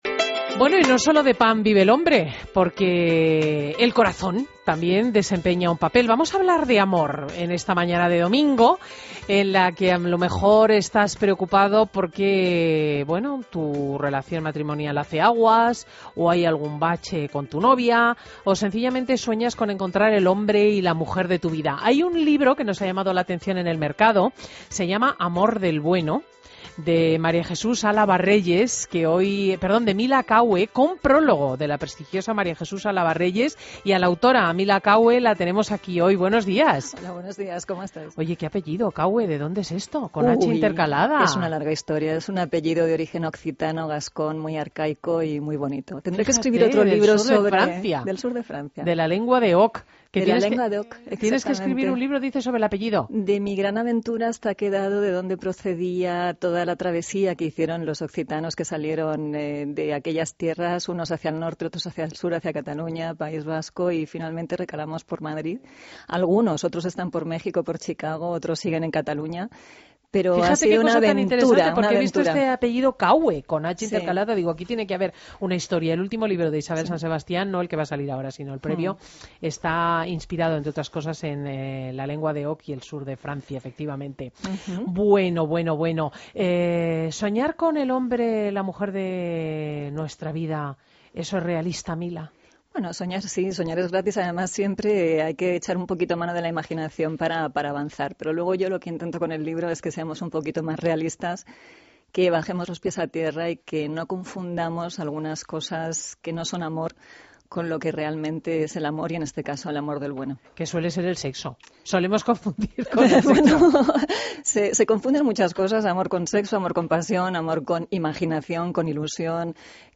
En la mañana del domingo estuvimos compartiendo con Cristina L. Schlichting, en su programa de fin de semana de la COPE, un ratito para comentar el libro Amor del Bueno. Una entrevista muy interesante, que me gustaría agradecer a Cristina y a todo su equipo por su amabilidad.